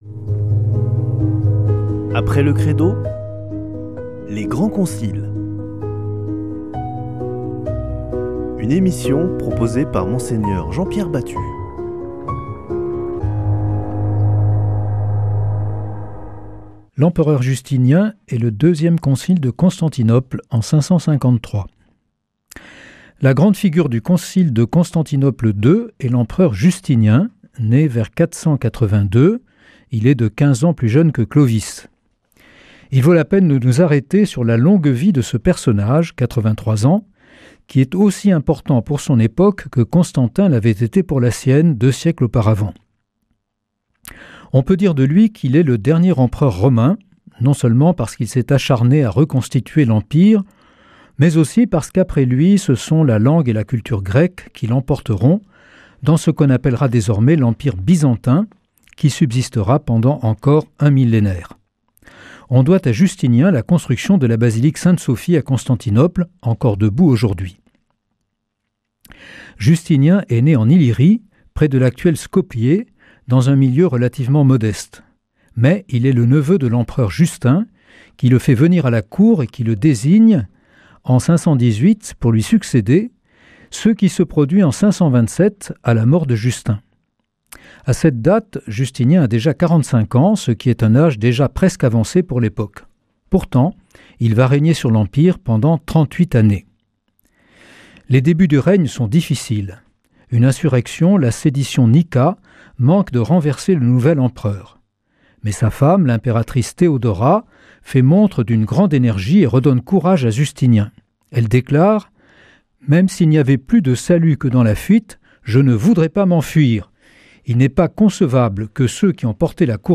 Une émission présentée par Mgr Jean-Pierre Batut Evêque auxiliaire de Toulouse